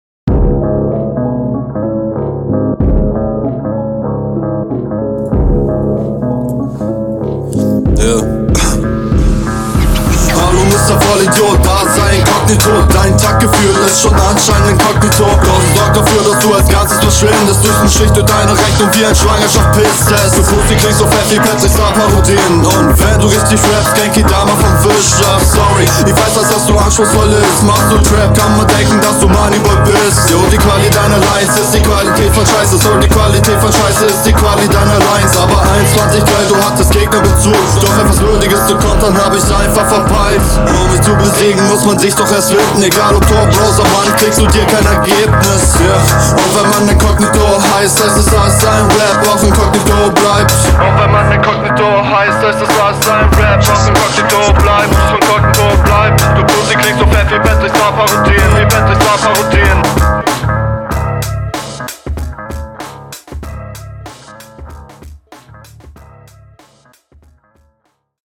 Schneid die Mausklicks und andere Störgeräusche am Anfang mal raus.
Flow: stabyl, rattert durch den beat Text: ganz gut Soundqualität: meh Allgemeines: ist ganz geil …
Flow: stimme echt gut und flouw auch echt zu gut für bronze Text: bei'm text …